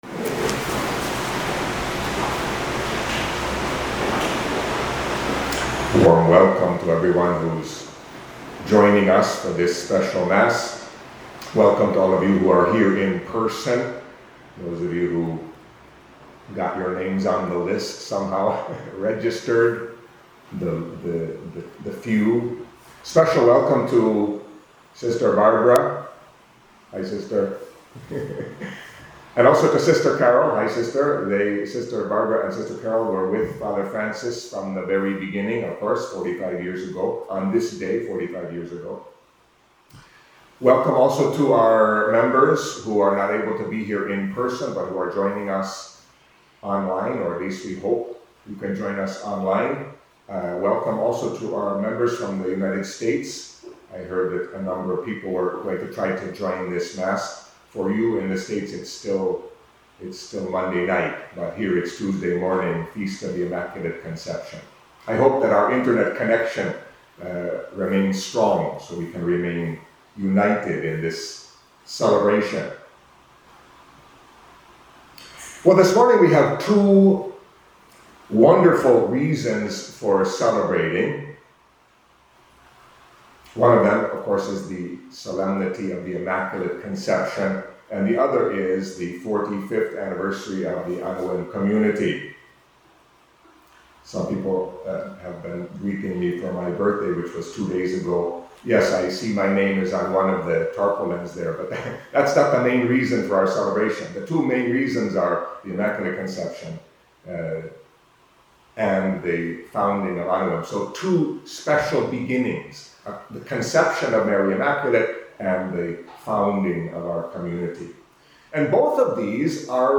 Catholic Mass homily for the Solemnity of the Immaculate Conception